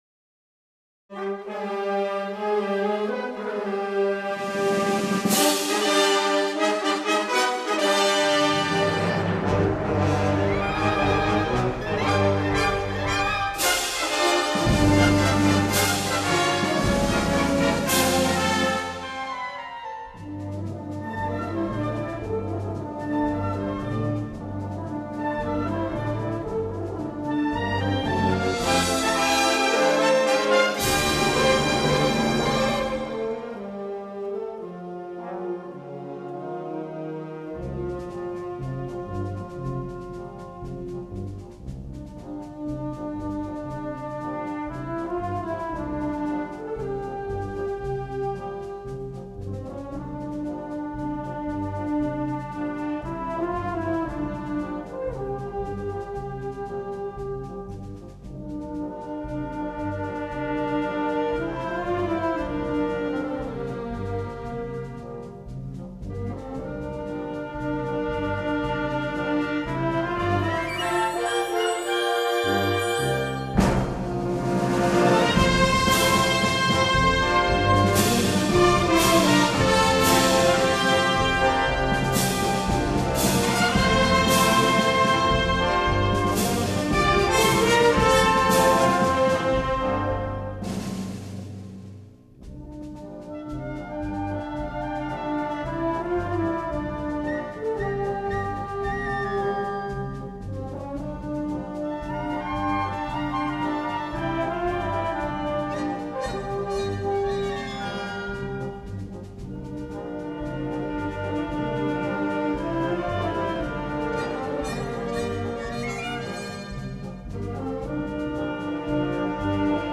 Gender: Moorish marches